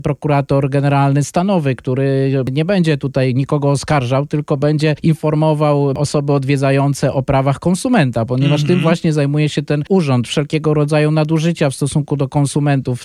W studiu Radia Deon Konsul Michał Arciszewski serdecznie zaprasza na Dni Poradnictwa ZUS w Chicago, które odbędą się w dniach 15-17 grudnia 2023 r. w siedzibie Związku Narodowego Polskiego (PNA) przy 6100 N Cicero Ave.